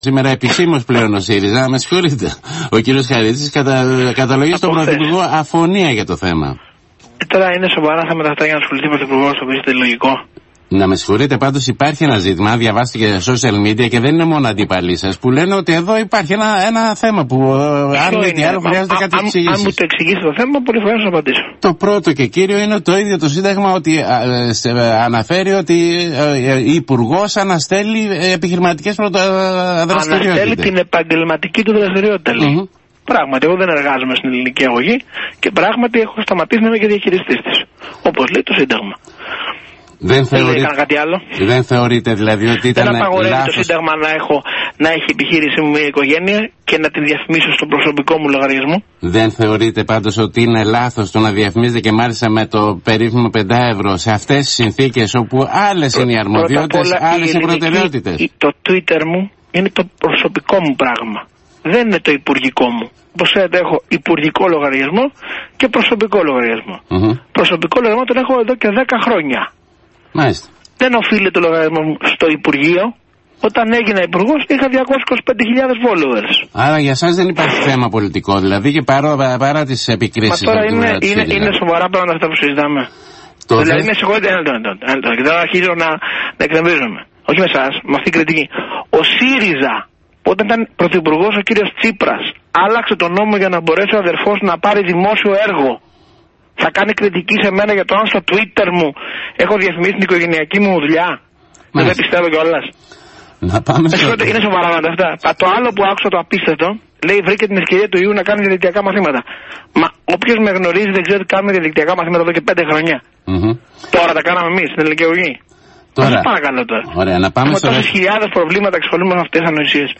ο Υπουργός ανάπτυξης Αδωνις Γεωργιάδης